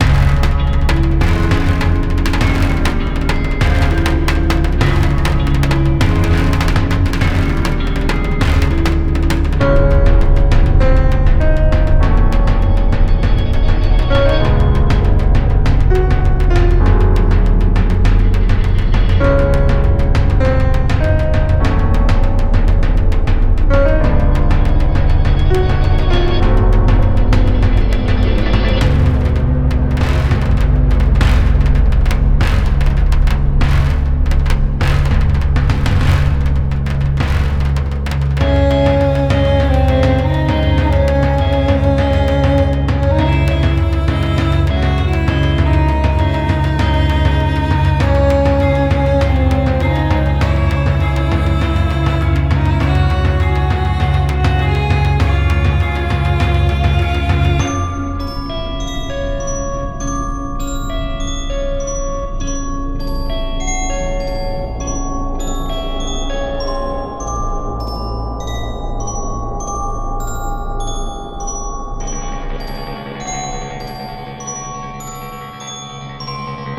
そんなシーンが自然と浮かぶ、ダークで儀式的なムードが特徴です。
静けさの中に潜む緊張感。じわじわと迫るような威圧感。
• テンポ：BPMおよそ60前後のスローな進行
• 拍子：4/4拍子、ゆったりとした脈動感
• 和声：ディミニッシュコードやサスペンデッドコードを多用し、不安定感を演出
- 金属系の打楽器（ガムラン風）で神秘性を表現
- 深くリバーブを効かせたパッドで空間演出
- 時折入る鐘の音が“儀式感”を高める